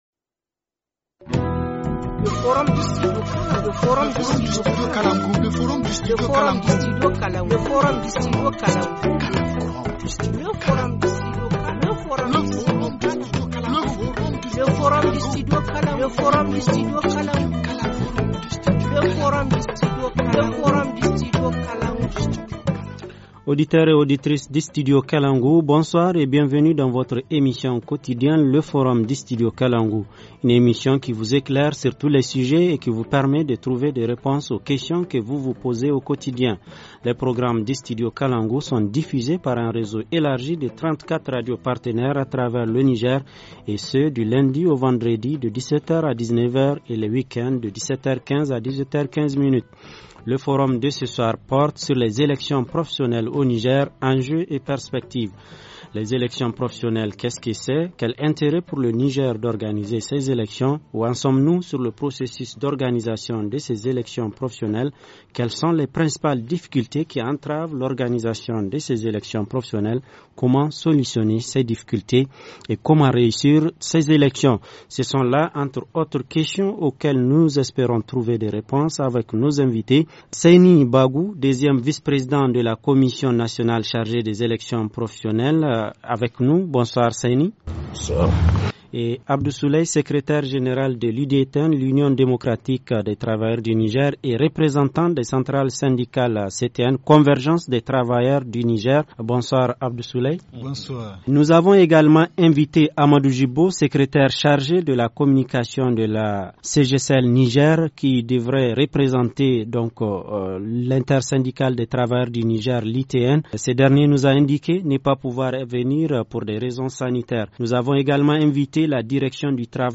Forum 31/01/2018 : Les élections professionnelles au Niger : enjeux et perspectives - Studio Kalangou - Au rythme du Niger